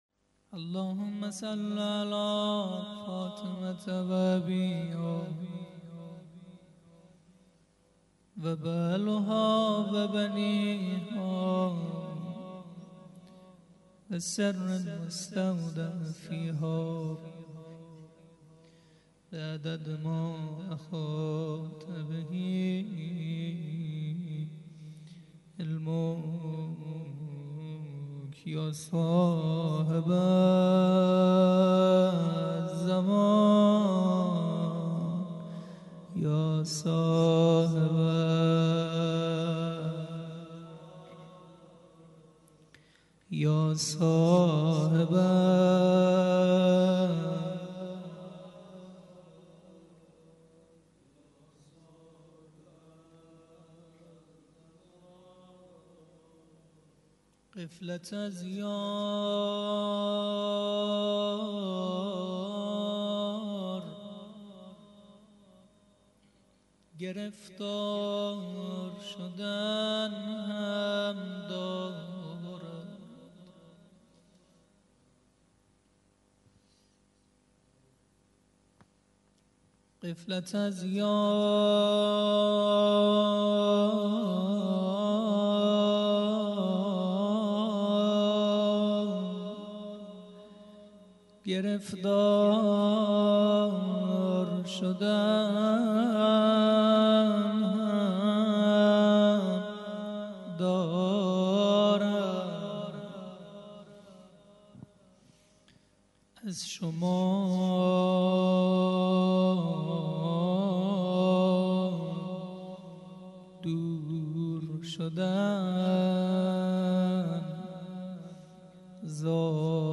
روضه - زیارت عاشورا 01.mp3